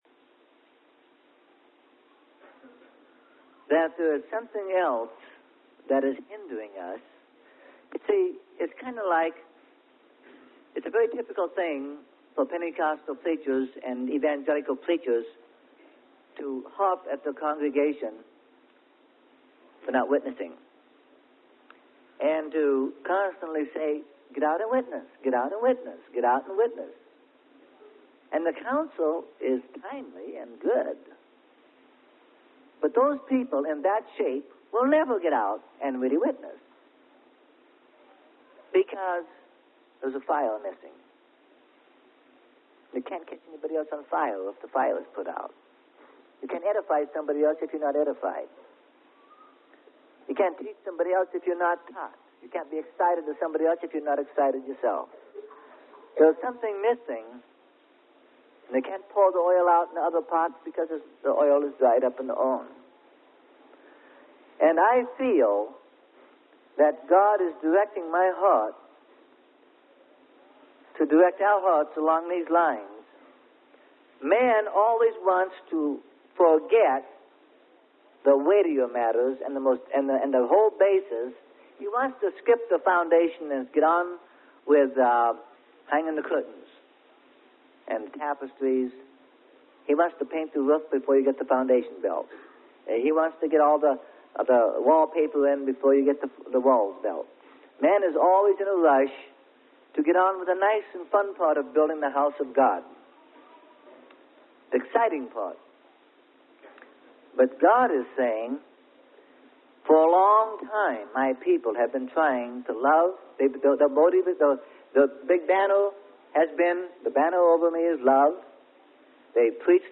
Sermon: Whatever Became Of Sin?